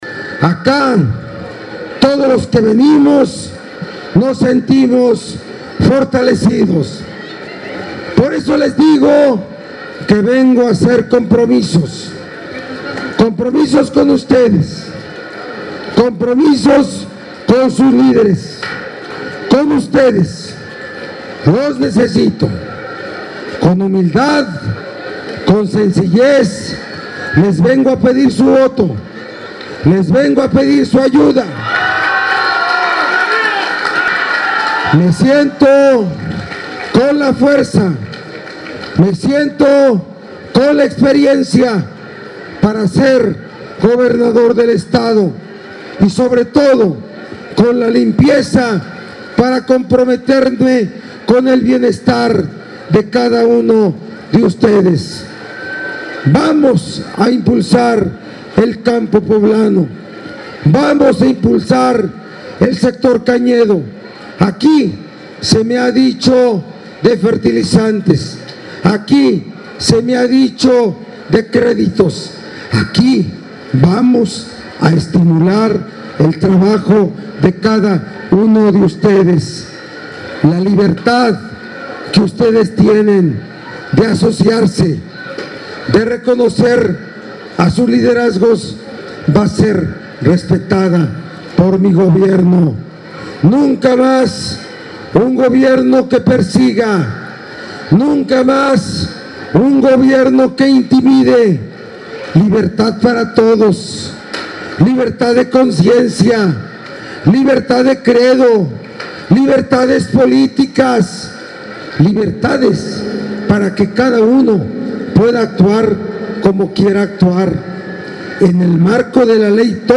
En este mitin multitudinario, Barbosa Huerta asentó que se impulsará al sector cañero mediante fertilizantes y créditos, “aquí vamos a estimular el trabajo de cada uno de ustedes.